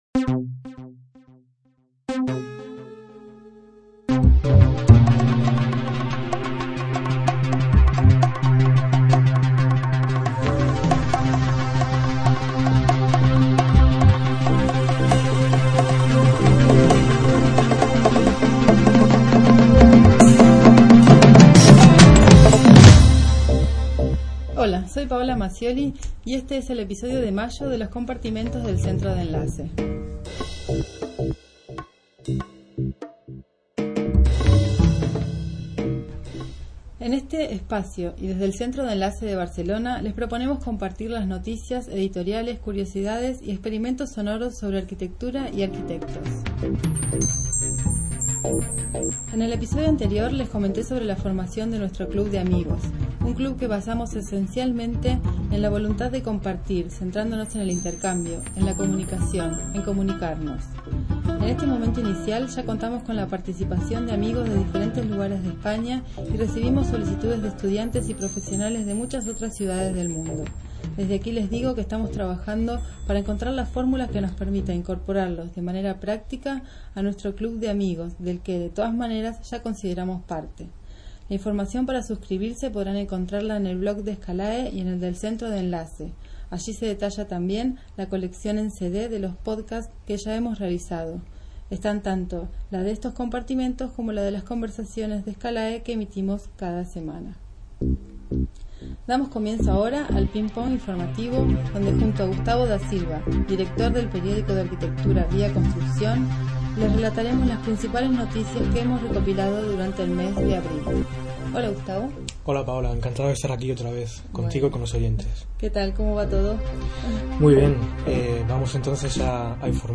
magazine de estilo radiofónico sobre arquitectura
los sonidos del puente aéreo BCN-MAD